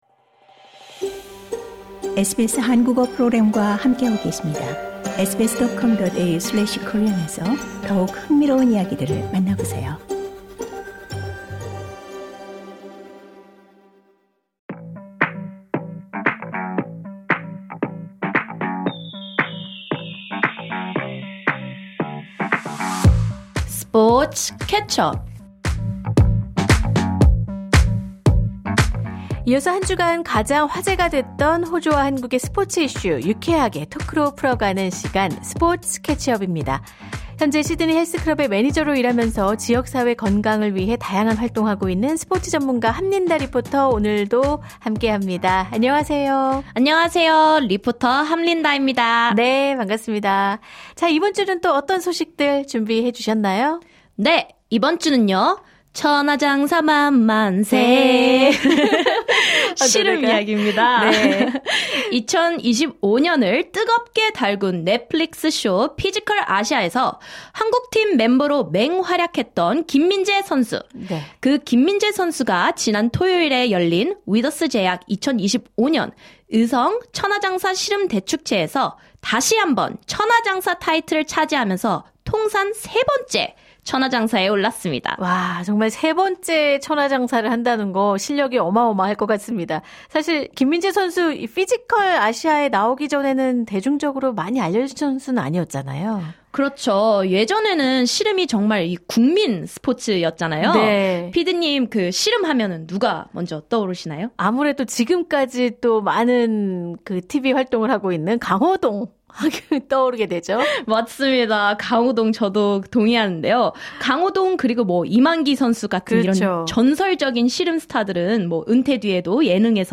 Transcript 이어서 한 주간 가장 화제가 됐던 호주와 한국의 스포츠 이슈 유쾌하게 토크로 풀어가는 시간 스포츠 캐치업입니다.